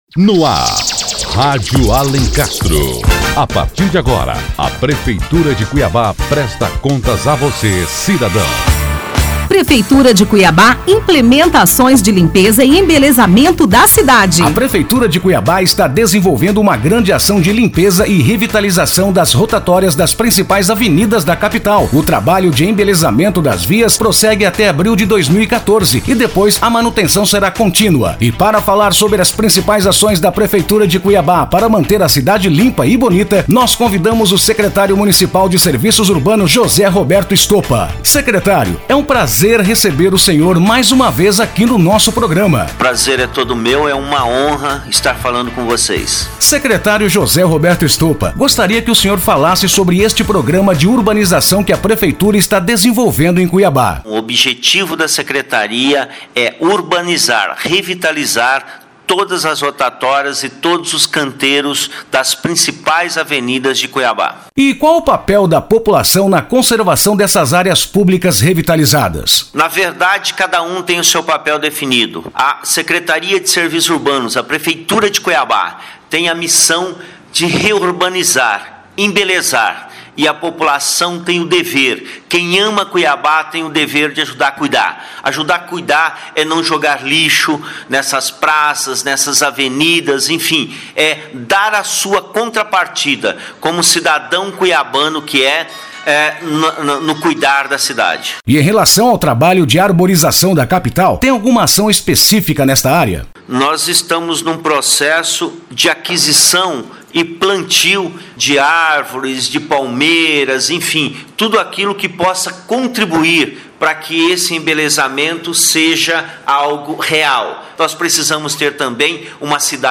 O Secretário Municipal de Serviços Urbanos, José Roberto Stopa, fala sobre as principais ações para manter a...